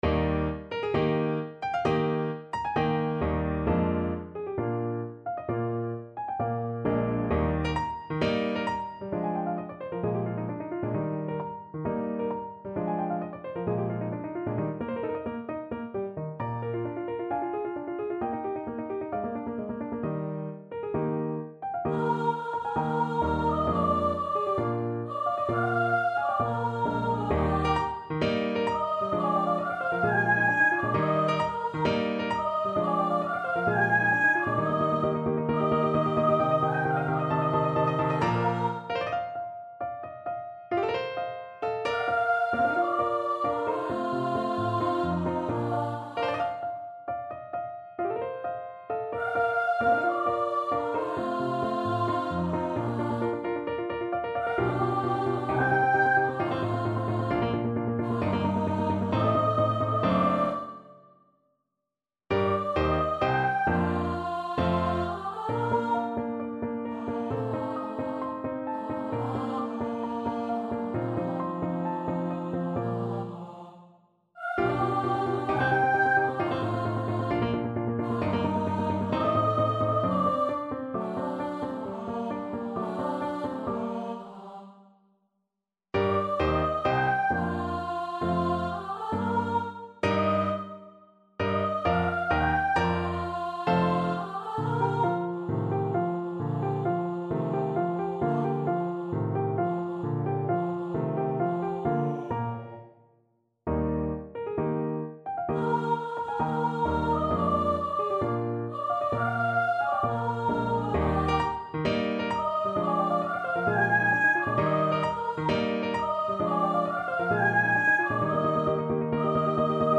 Free Sheet music for Soprano Voice
Soprano Voice
Eb major (Sounding Pitch) (View more Eb major Music for Soprano Voice )
~ = 132 Allegro (View more music marked Allegro)
4/4 (View more 4/4 Music)
Classical (View more Classical Soprano Voice Music)